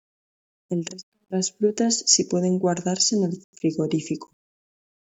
Pronounced as (IPA) /ˈresto/